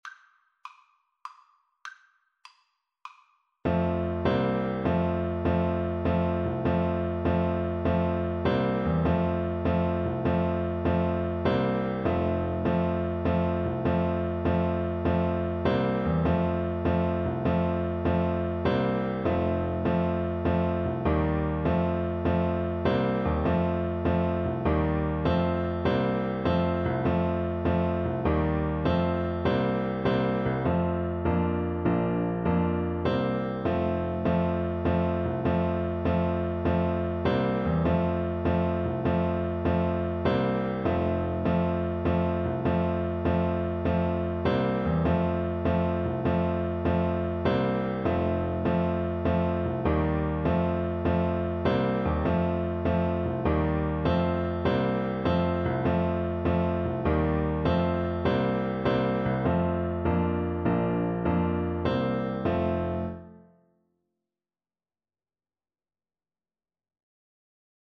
Traditional Music of unknown author.
9/8 (View more 9/8 Music)
F major (Sounding Pitch) (View more F major Music for Bassoon )